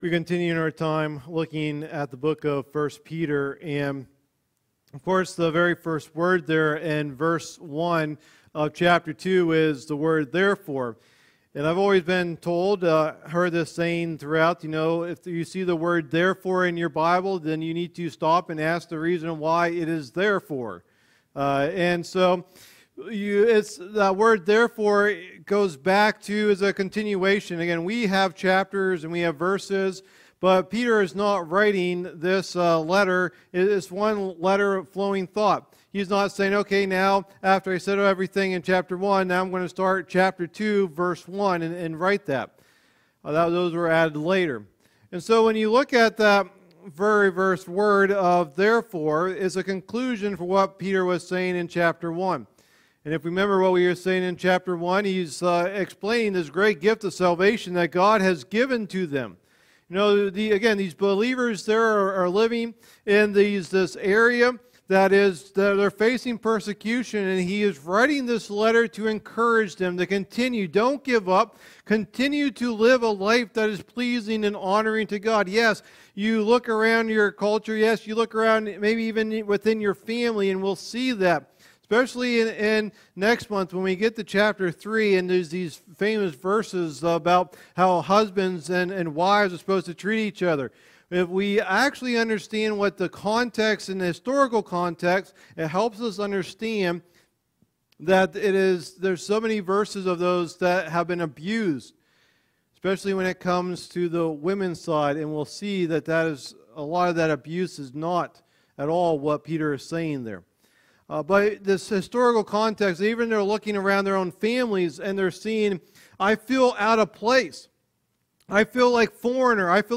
Sunday Morning Teachings | Bedford Alliance Church